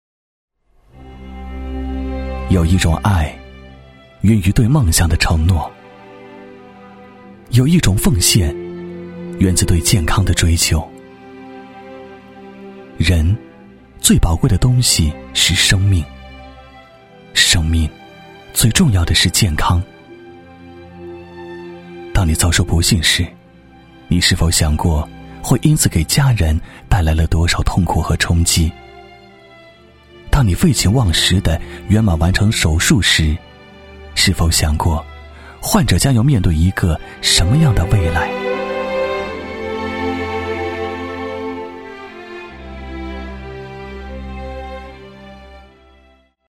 男国94_其他_微电影_独白运动康复.mp3